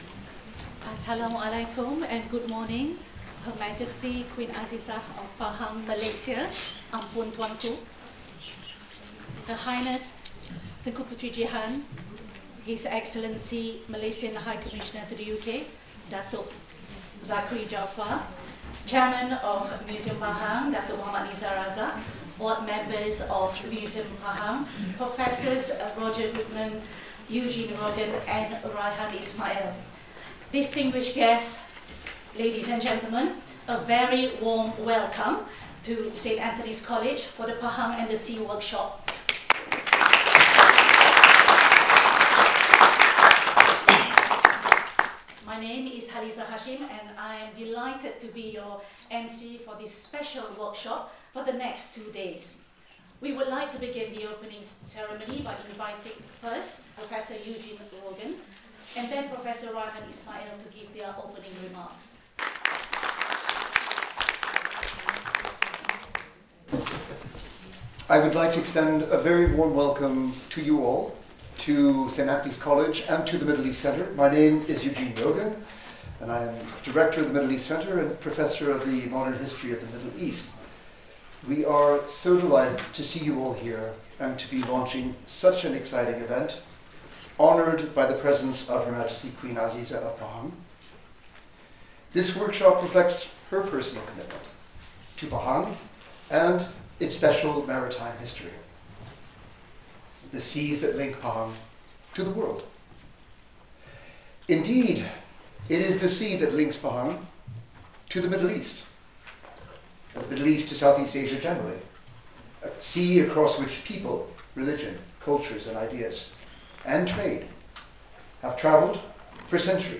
The workshop brought together experts in the field from the US, UK, Europe and Malaysia who shared their knowledge and research, as we explored the fascinating maritime history of Pahang, Malaysia and its connections with the Islamic world, China and Europe.
This keynote address by the Queen repositions Pahang as a sovereign maritime kingdom whose historical and legal significance has long been overshadowed by the western-oriented narratives of Melaka and other coastal polities.